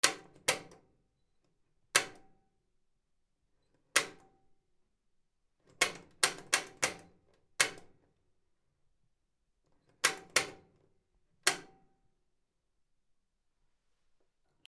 / M｜他分類 / L15 ｜スイッチ・ボタン(アナログ)
ハンドルを回す 手回し式のお風呂
カチカチD50